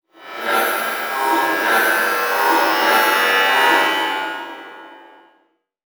UI Whoosh Notification 5.wav